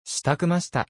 Japanese unit responses.
And couldn't resist making some Japanese unit voices with it.